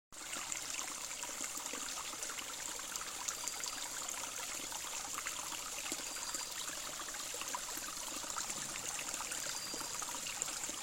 流动的溪流
描述：使用Zoom H4N进行潺潺流的现场录音。
标签： 小溪 溪流 流水声 现场记录 农村 液体 放松
声道立体声